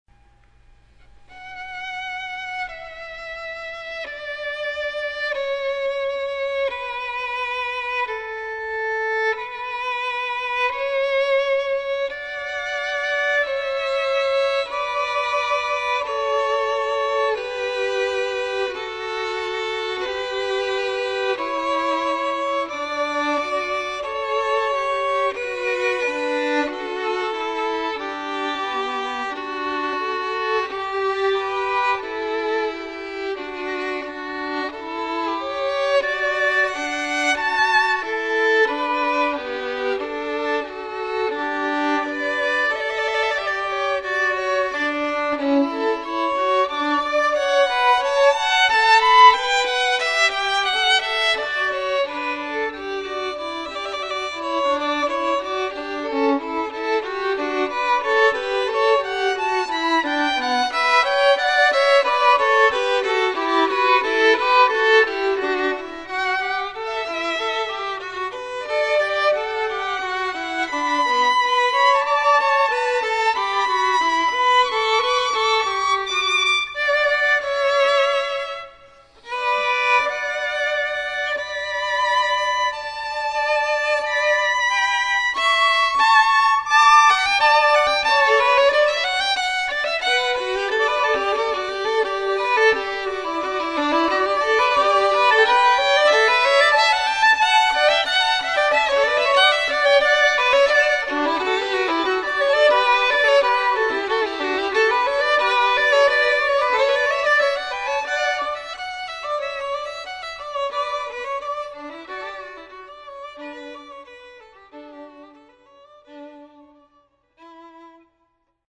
Two Violins
TwoViolins_Pachelbel'sKanon.mp3